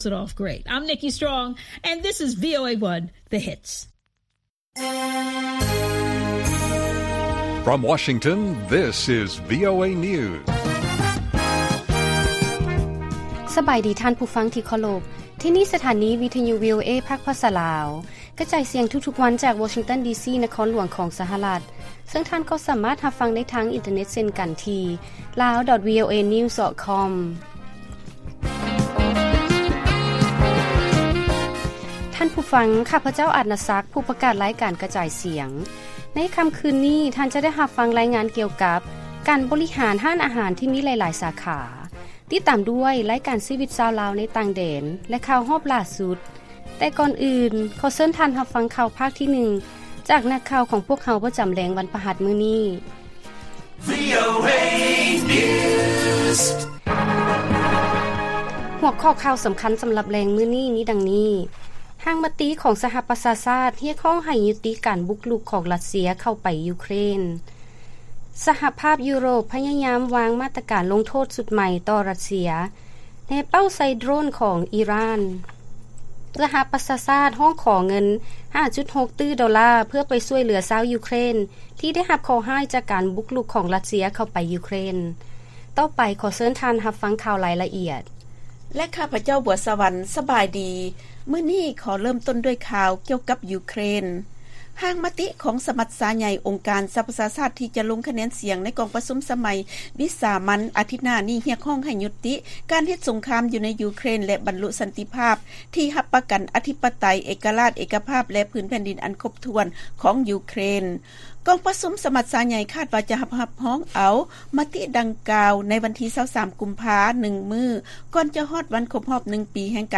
ລາຍການກະຈາຍສຽງຂອງວີໂອເອ ລາວ: ຮ່າງມະຕິຂອງສະຫະປະຊາຊາດ ຮຽກຮ້ອງໃຫ້ຍຸຕິການບຸກລຸກ ຂອງ ຣັດເຊຍ ເຂົ້າໄປໃນຢູເຄຣນ